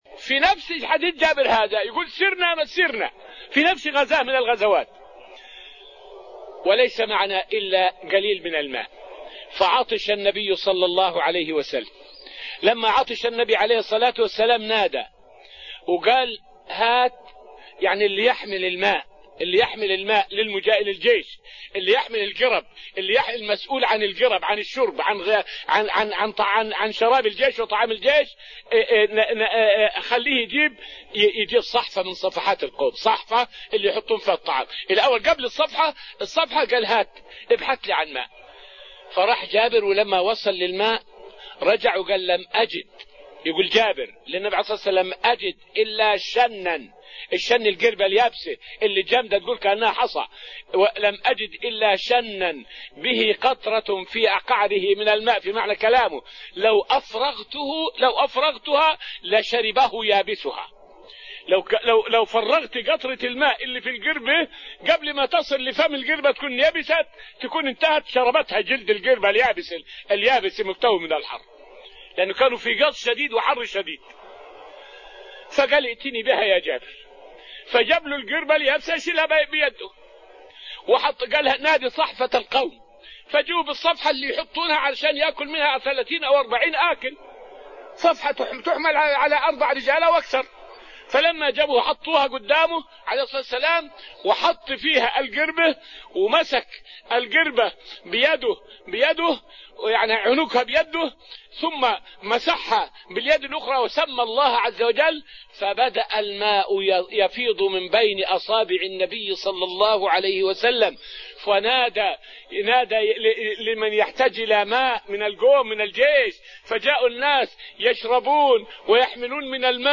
الأنفال السيرة النبوية تفسير تفسير القرآن دروس المسجد النبوي
فائدة من الدرس الثامن من دروس تفسير سورة الأنفال والتي ألقيت في رحاب المسجد النبوي حول معجزة نبع الماء من أصابع نبينا محمد.